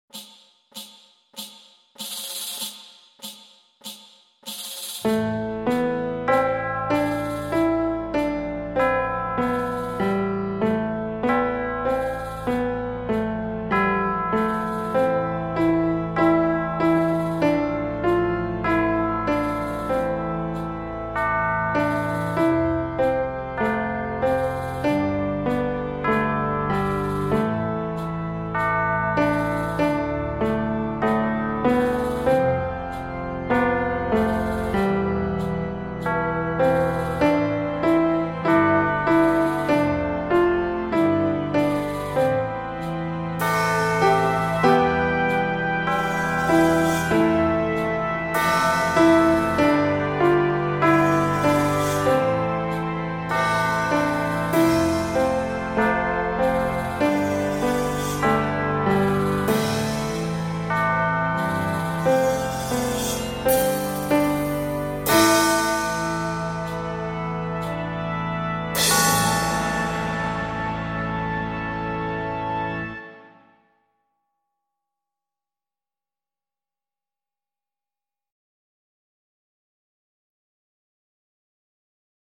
Accomp